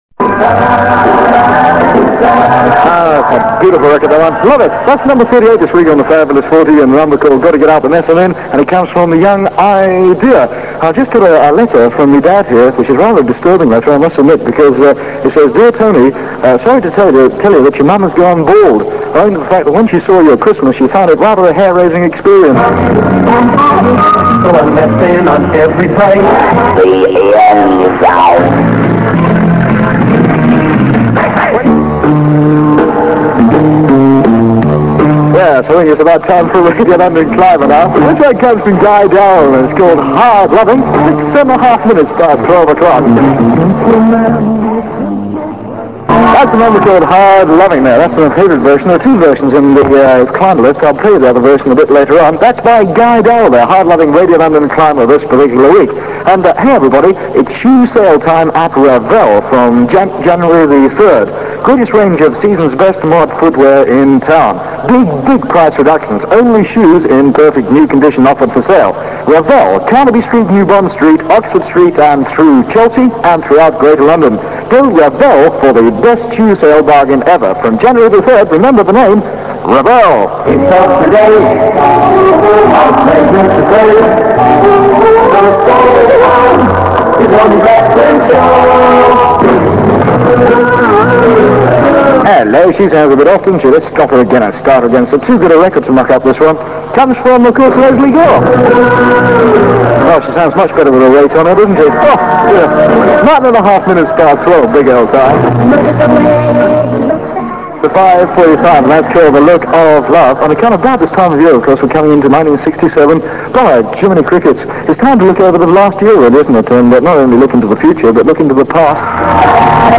click to hear audio Tony presenting the 12-3pm show on Big L, 29th December 1966 (duration 3 minutes 3 seconds)